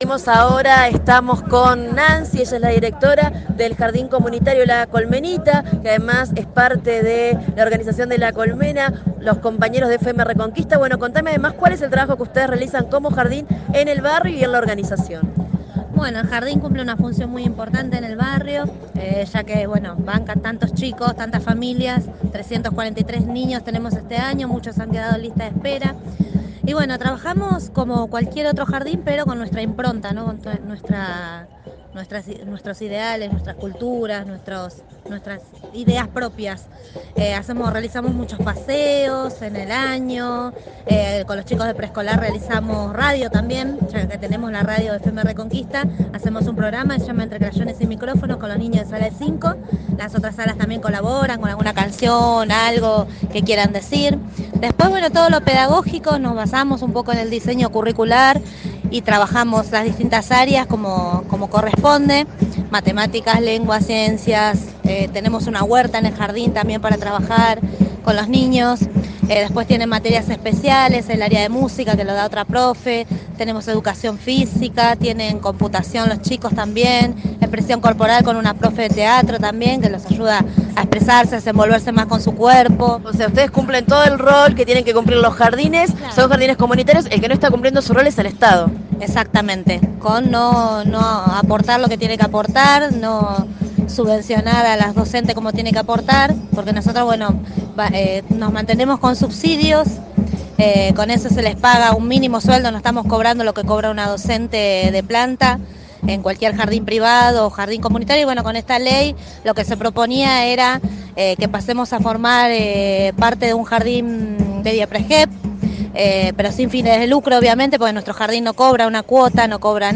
Móvil de FM Reconquista: